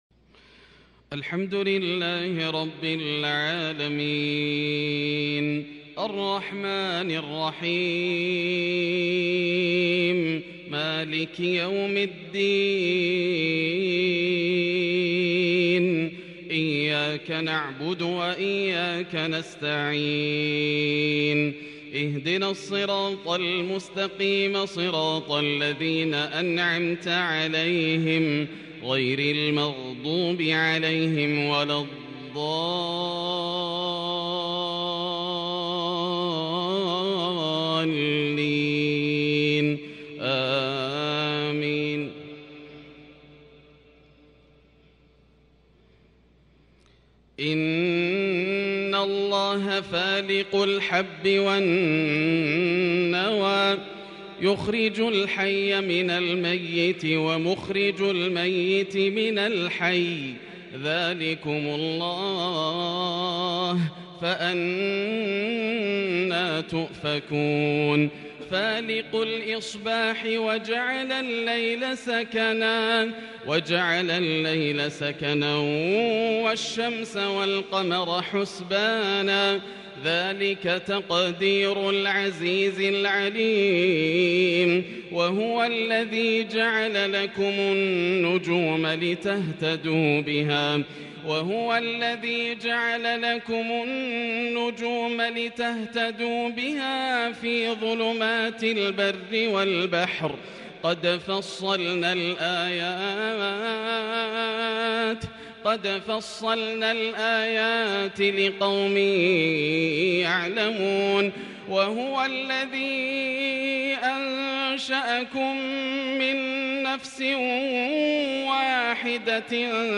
من سورتي الأنعام و الحشر | Isha prayer from Surah AlAn'am and AlHashr| 12/2/2021 > 1442 🕋 > الفروض - تلاوات الحرمين